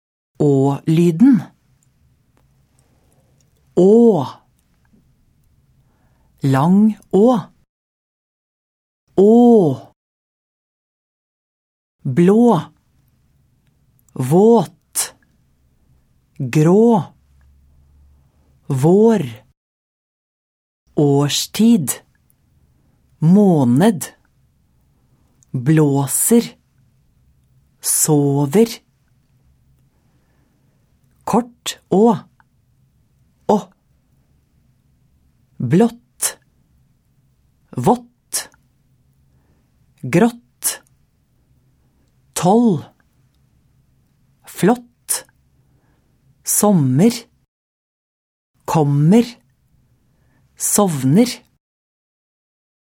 Uttale: Å-lyden (s. 90-91)